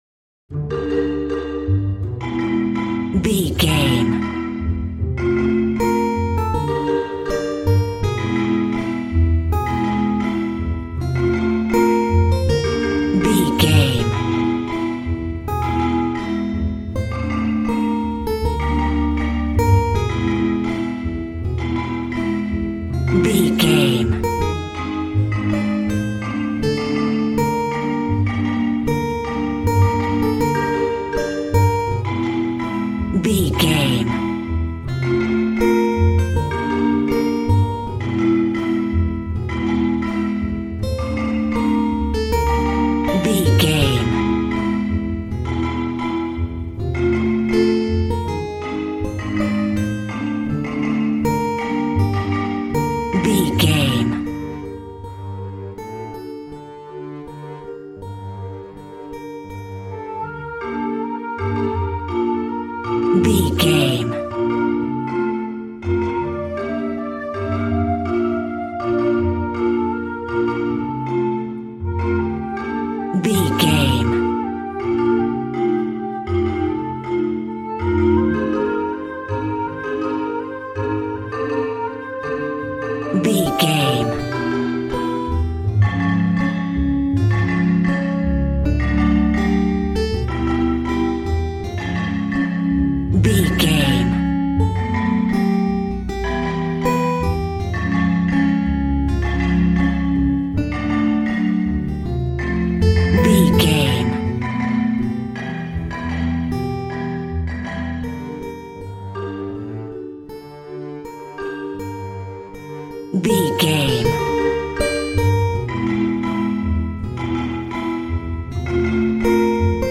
Ionian/Major
B♭
cheerful/happy
joyful
drums
acoustic guitar